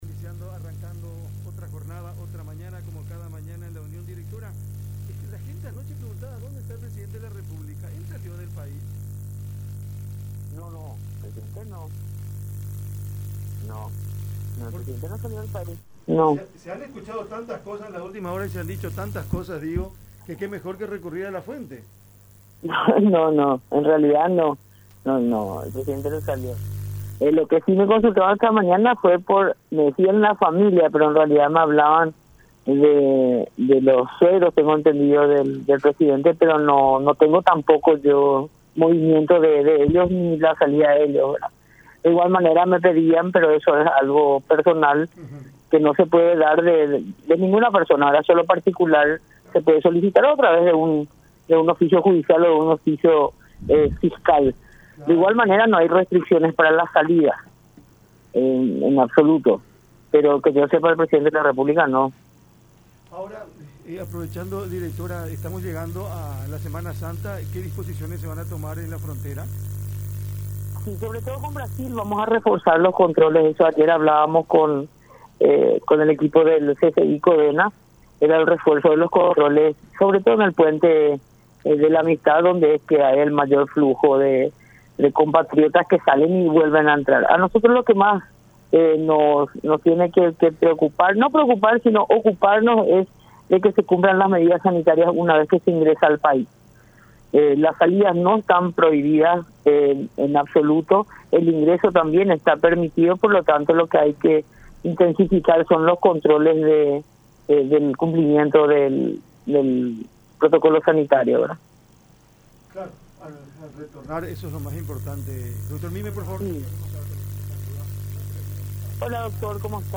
Lo que hay que intensificar son los controles”, dijo Ángeles Arriola, titular de Migraciones, en contacto con La Unión.